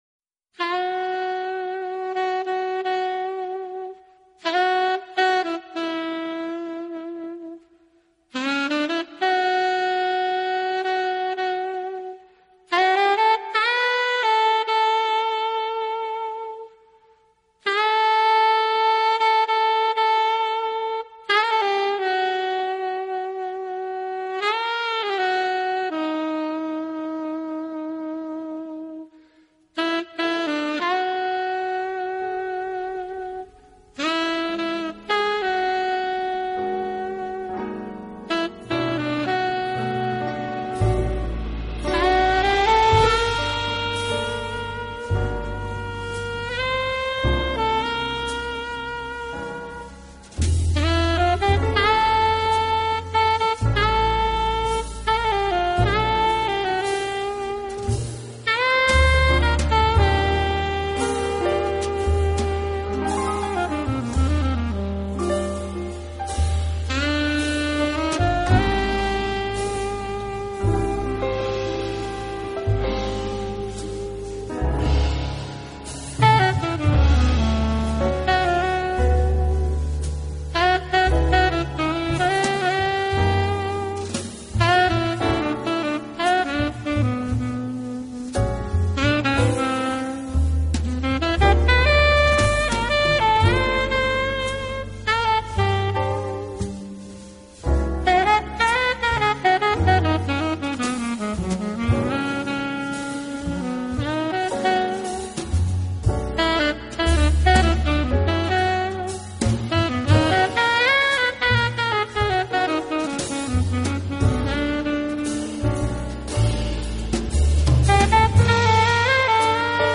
Studio/Live  Studio
Mono/Stereo  Stereo
drums
soprano & alto saxophone
bass
piano